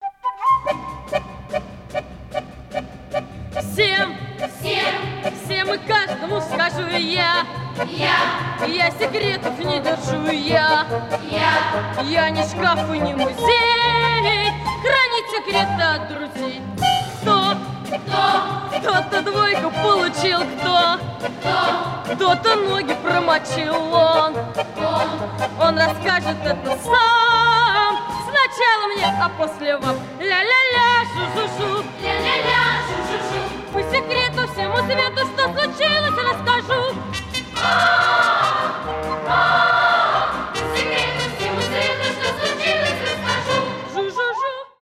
Песня из фильма